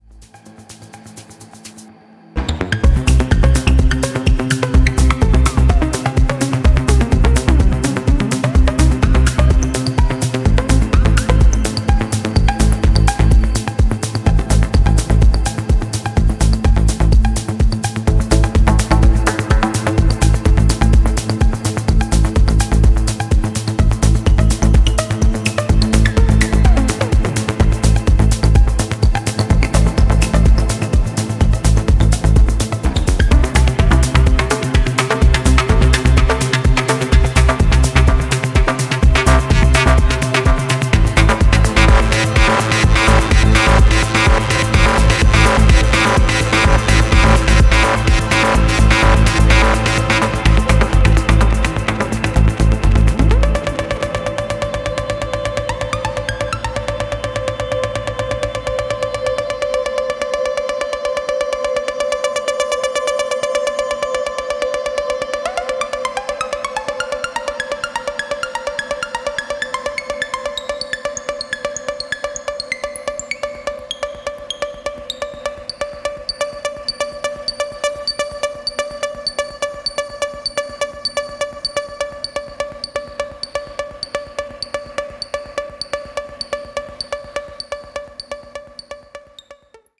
ソリッド&ヒプノティックなトラックに仕立てたそちらも、間違いない仕上がりです！